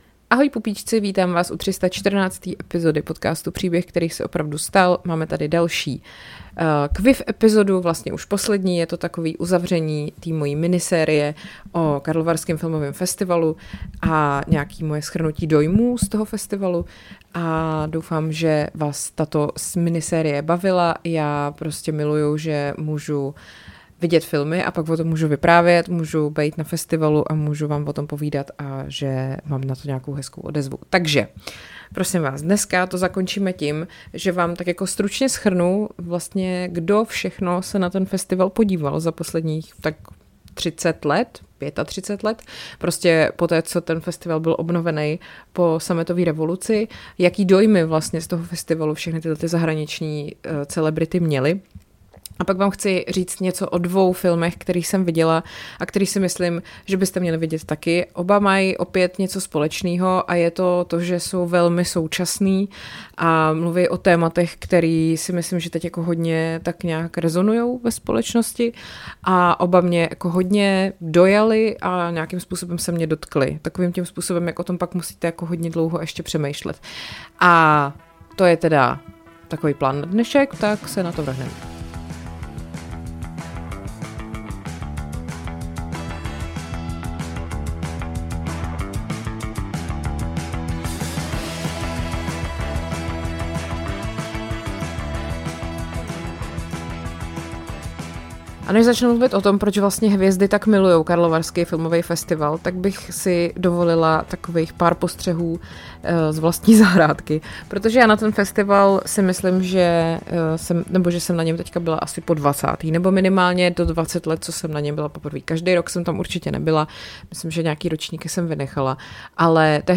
Audio referáty o tom, co jste ani netušili, že vás bude bavit.